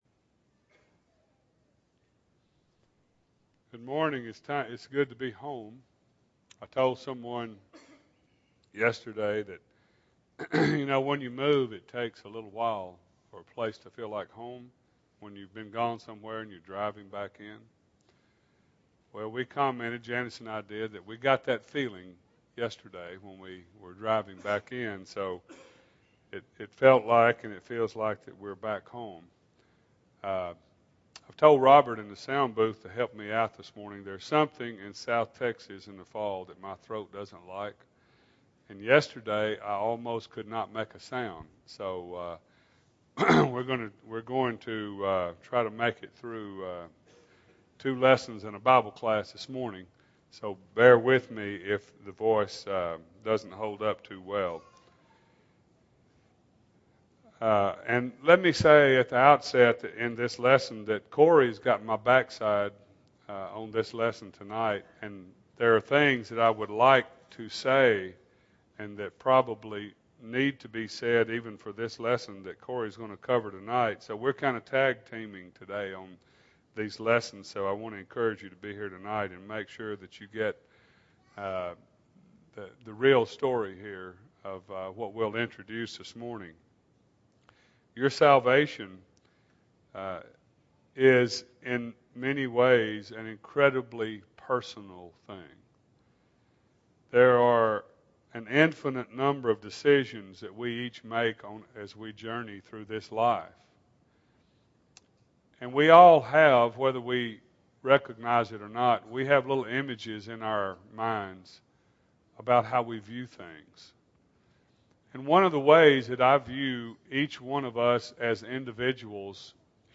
Sunday AM Sermon